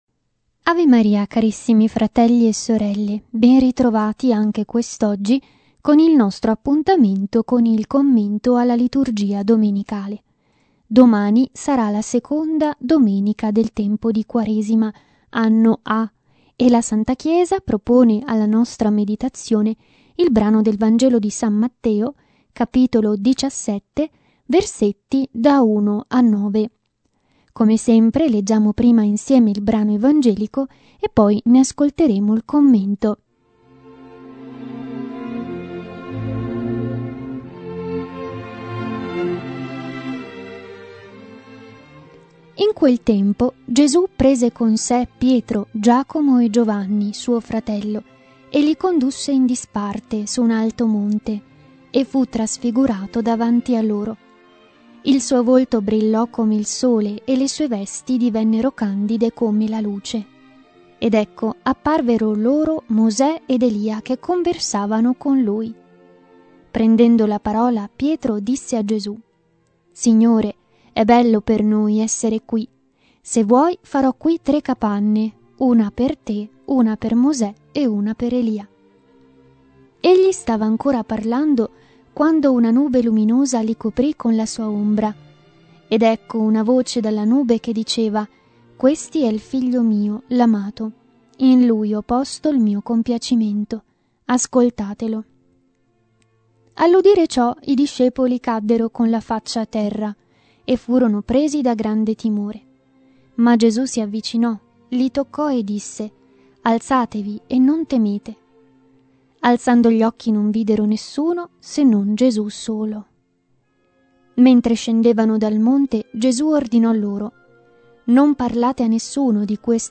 Genere: Commento al Vangelo Domenicale.